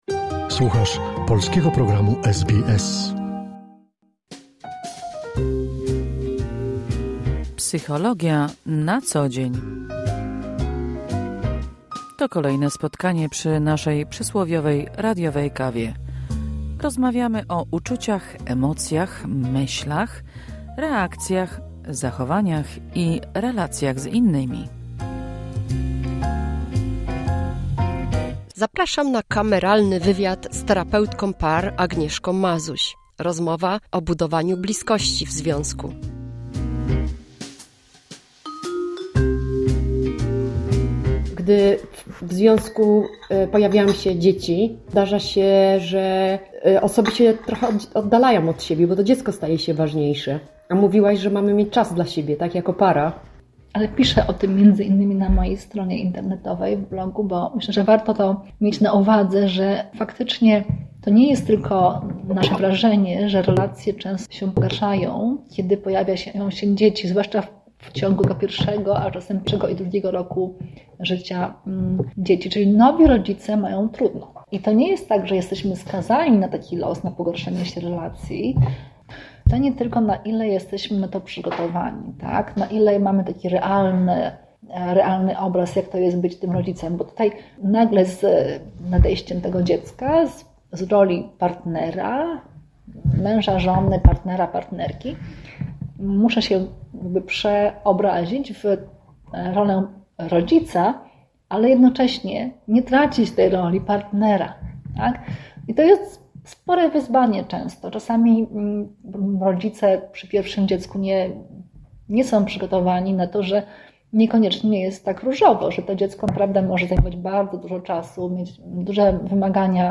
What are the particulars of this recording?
Kolejne spotkanie przy przysłowiowej radiowej kawie z cyklu „Psychologia na co dzień”.